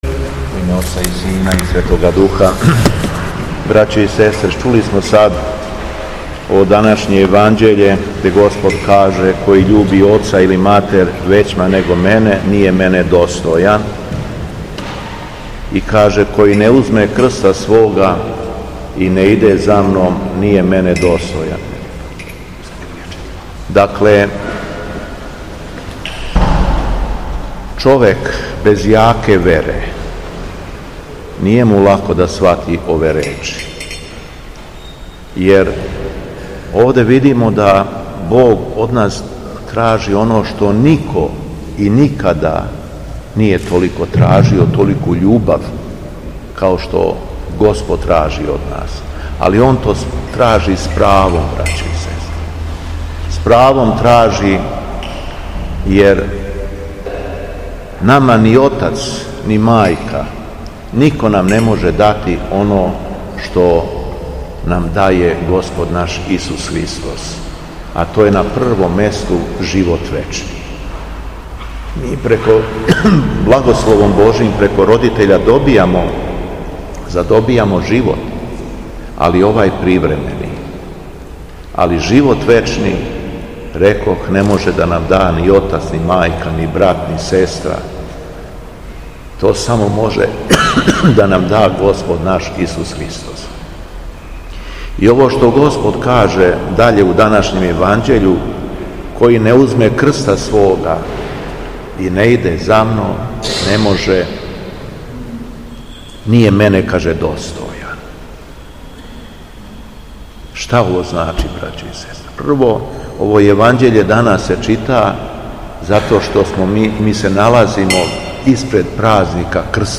Беседа Његовог Преосвештенства Епископа шумадијског г. Јована
После прочитаног јеванђелског зачала, Преосвећени Владика је у својој беседи рекао: